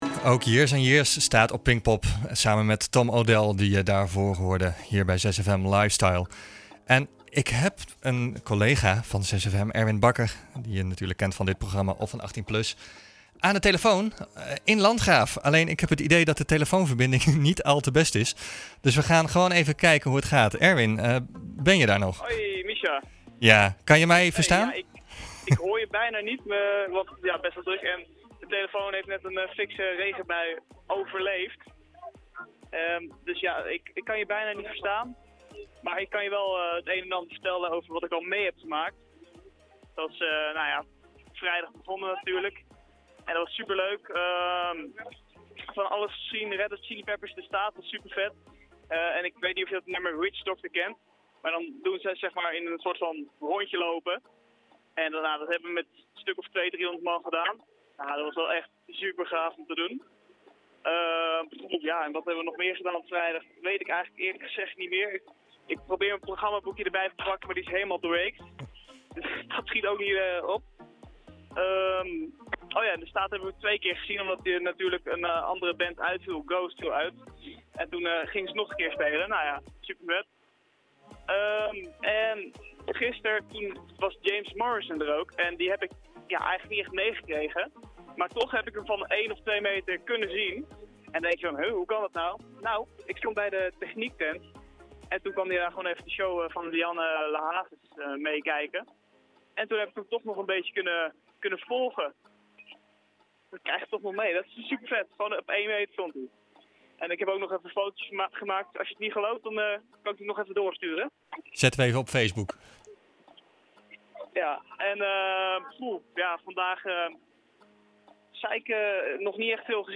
Gelukkig is een echte festivalganger inventief, en doet een echte radiomaker dan toch even verslag..
6fm-lifestyle-regen-pinkpop.mp3